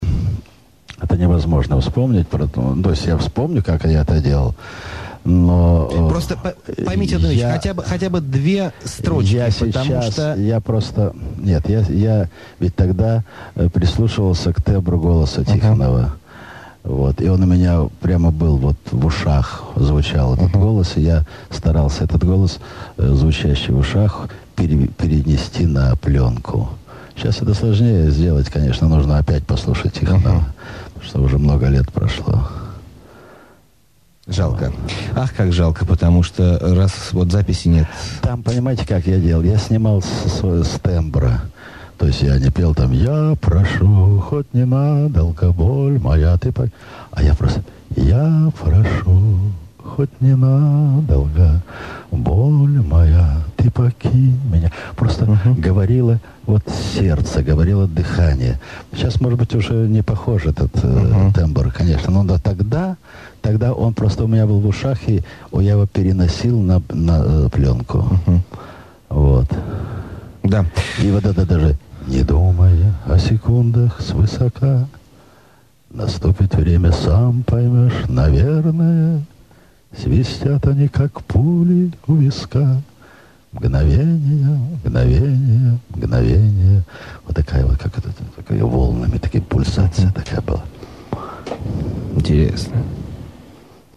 Крошечный кусочек у меня где-то есть, с передачи... Про Мгновения....
Там в самом конце он напевает, да так, что все переворачивается...